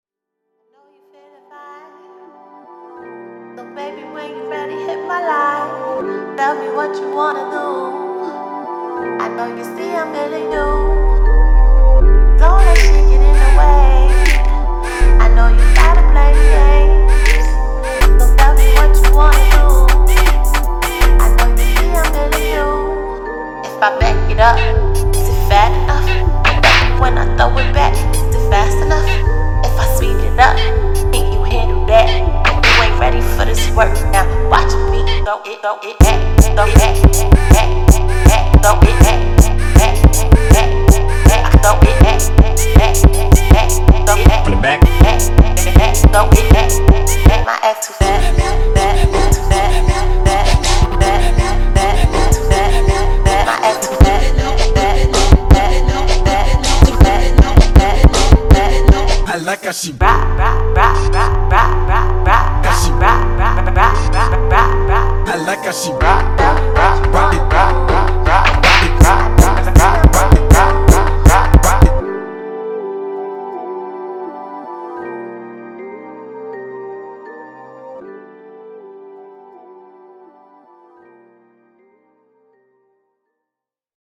это энергичная и зажигательная песня в жанре кид-кор